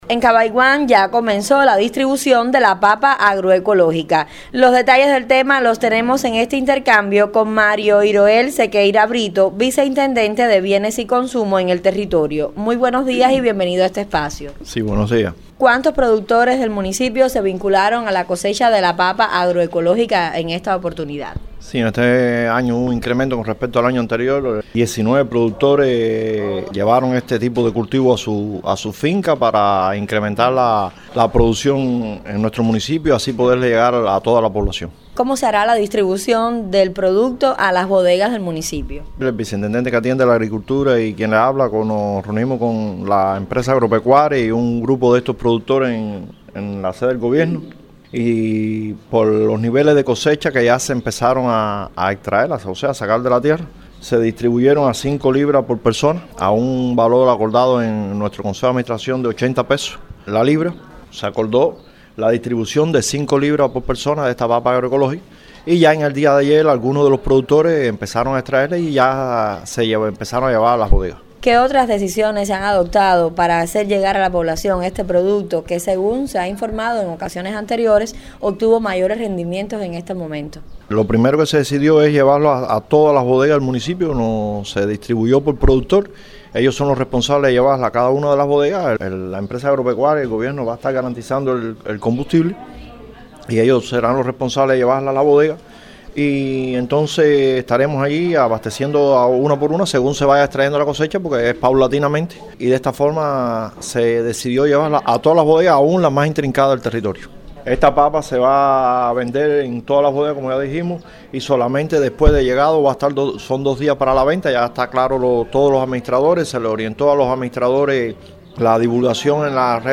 entrevista
La distribución de la papa ecológica ya comenzó en las distintas bodegas del territorio. Sobre el particular conversamos con Mario Zequeria Brito, ViceIntendente de Bienes y Consumo del Consejo de la Administración Municipal de Cabaiguán.